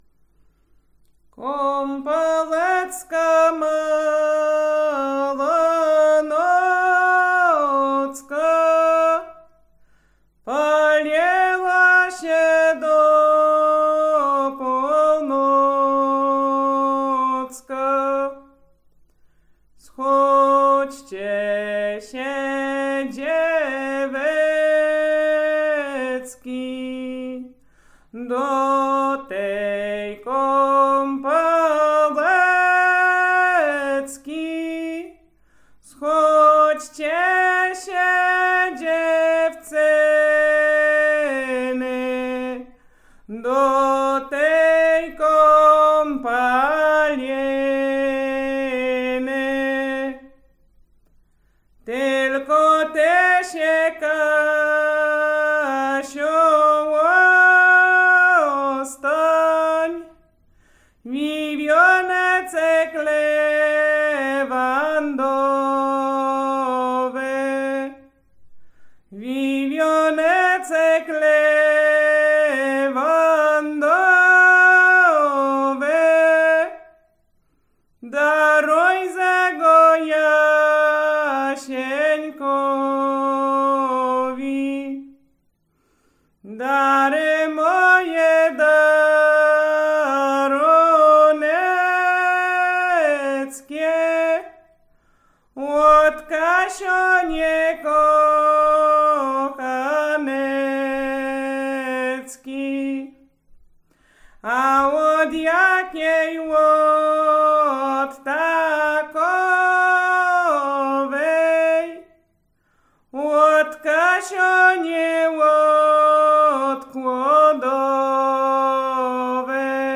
Lubelszczyzna
kupalskie lato sobótkowe świętojańskie